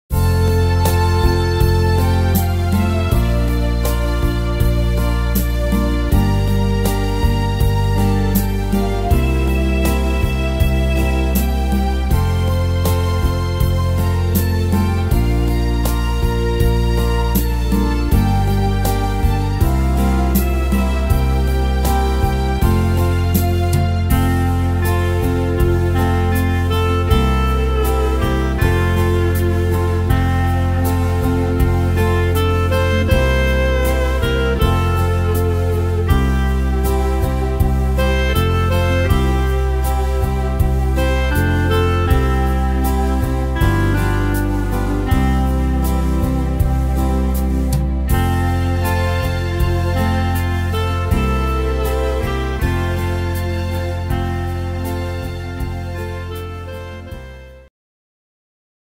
Tempo: 80 / Tonart: F-Dur